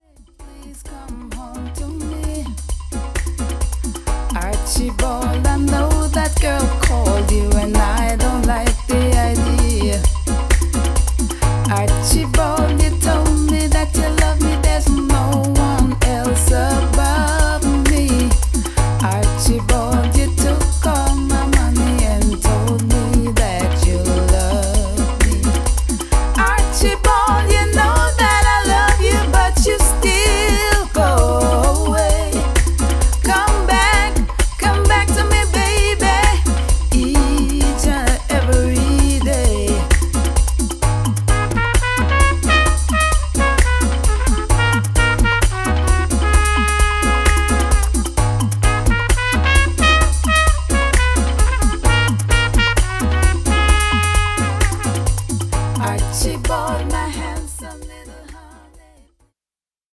ホーム ｜ JAMAICAN MUSIC > UKレゲエ/ラバーズ